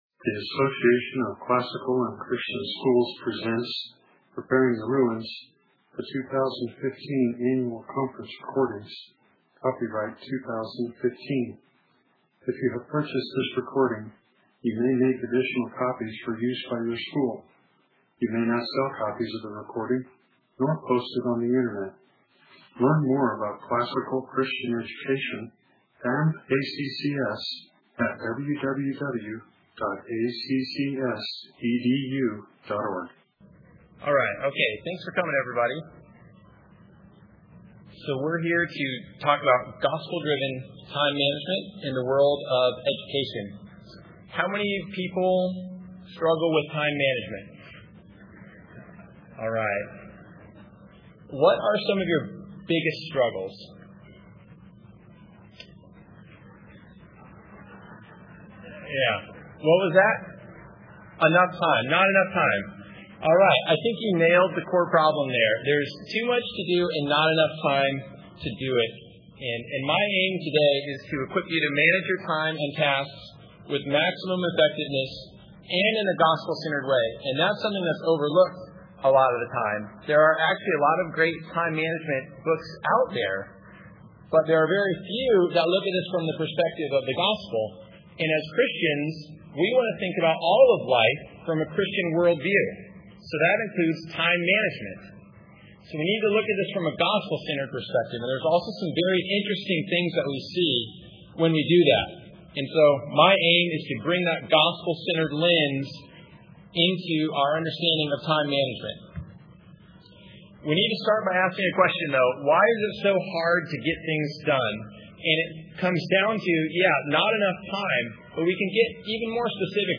2016 Practicum Talk, 0:55:30, 7-12, K-6, Leadership & Strategic, Training & Certification
Dec 19, 2018 | 7-12, Conference Talks, K-6, Leadership & Strategic, Library, Practicum Talk, Training & Certification | 0 comments
In this message we will discover the specific principles from the Scriptures and common grace that underpin any effective, gospel-centered approach to leadership, especially as it applies to the administration of Christian schools. Speaker Additional Materials The Association of Classical & Christian Schools presents Repairing the Ruins, the ACCS annual conference, copyright ACCS.